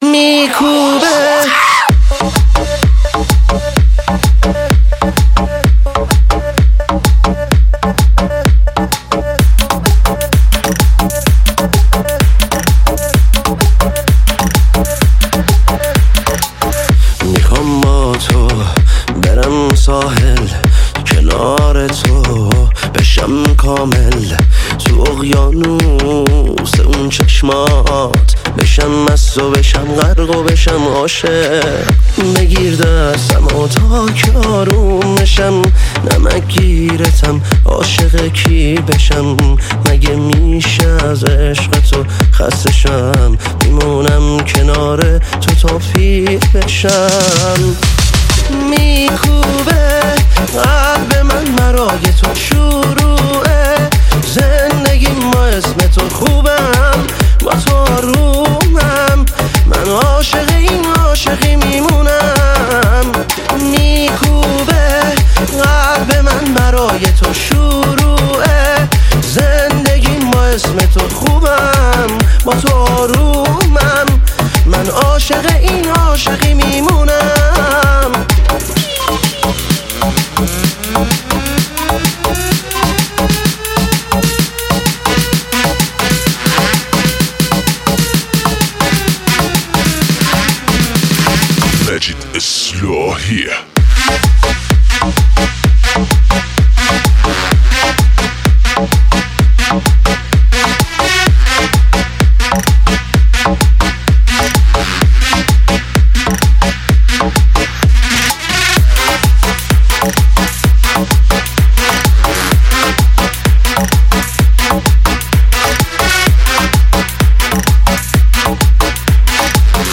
آهنگ های شاد ویژه شب یلدا